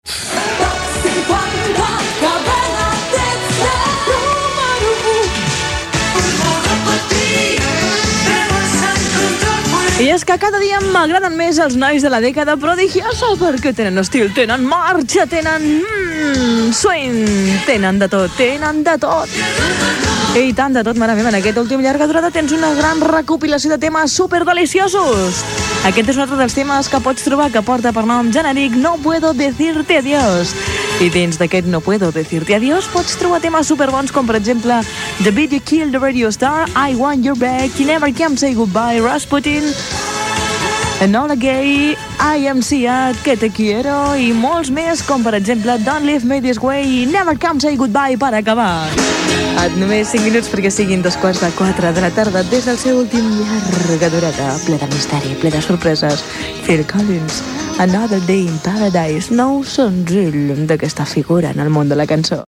Indicatiu del programa, comentari del grup La década prodigiosa, hora i presentació d'un tema musical
Musical
FM